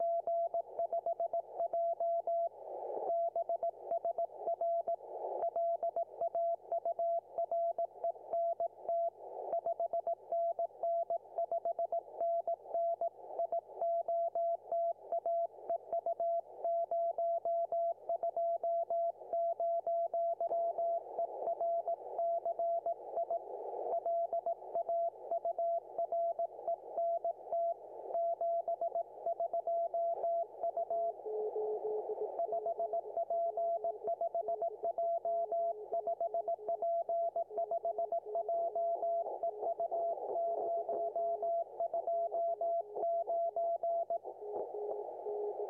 Equipement : TX-RX  FT-857D /  boite d’accord LDG-AT600 / interface SB-1000 de CG Antennas pour les modes digitaux / antenne G5RV half-size sur un mat télescopique de 9m ainsi qu'une verticale QRP MP-1 de chez Super Anetnnas.